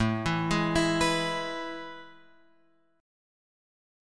AM7sus2 chord {5 7 6 x 5 7} chord